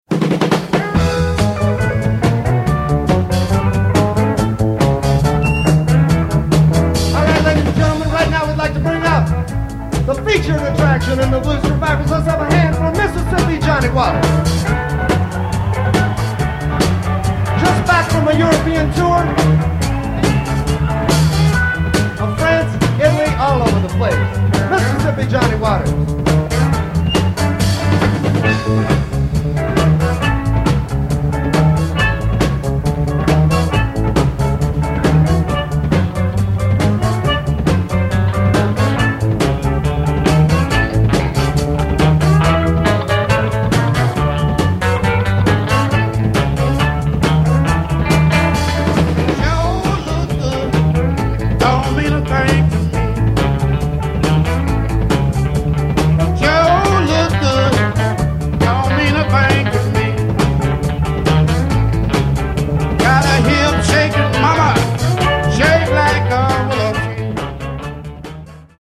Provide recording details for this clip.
Recorded in the 1980's and 1990's